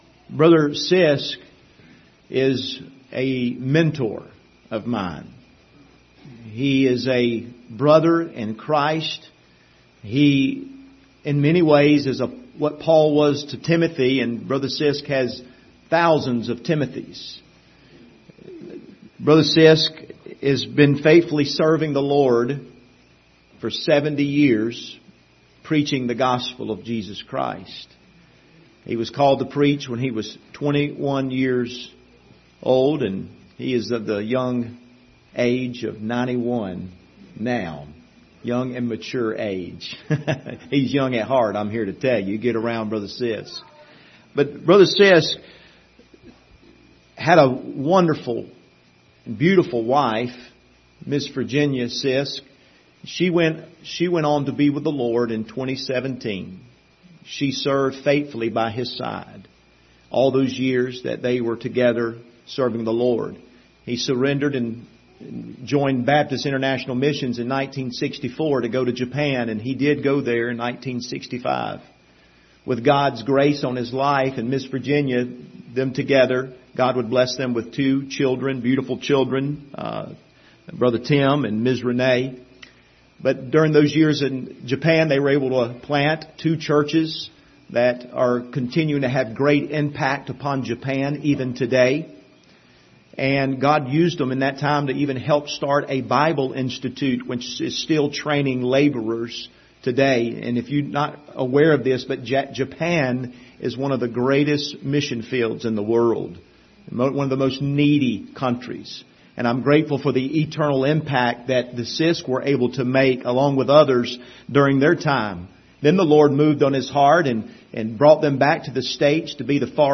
Passage: Ephesians 2:1-9 Service Type: Sunday Morning